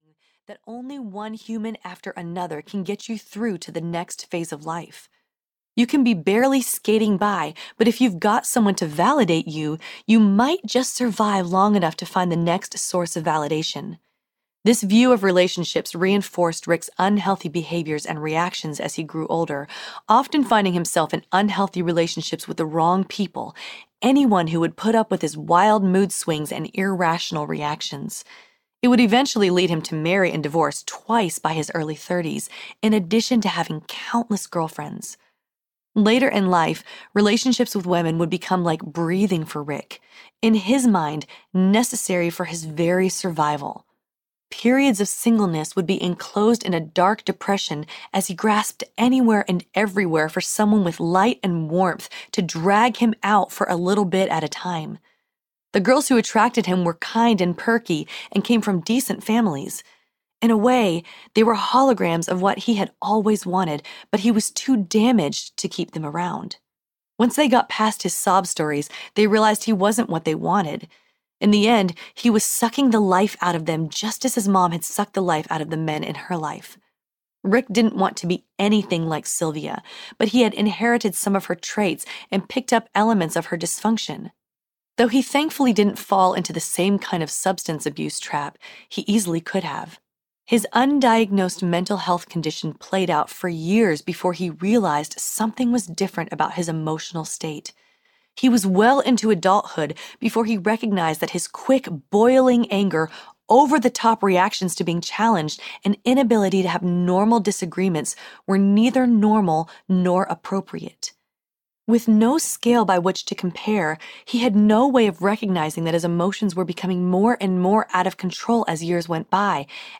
Leaving Cloud 9 Audiobook
Narrator
8.5 Hrs. – Unabridged